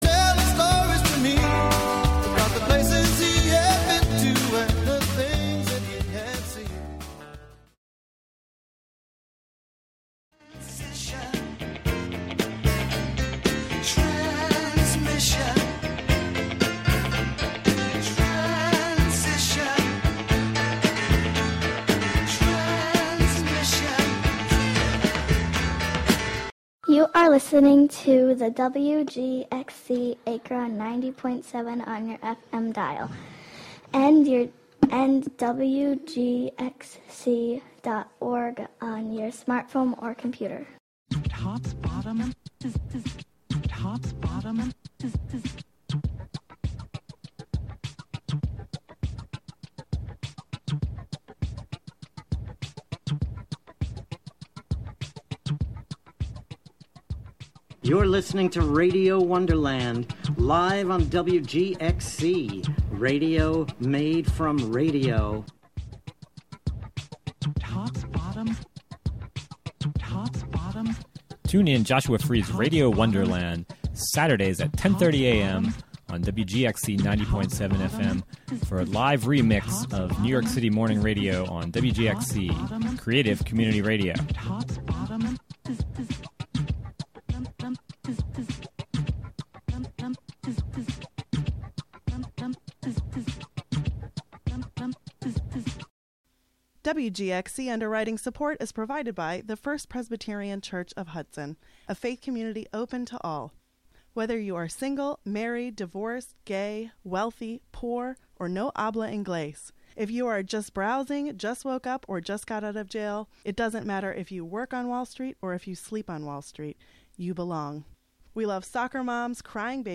"All Together Now!" is a daily news show brought to you by WGXC-FM in Greene and Columbia counties.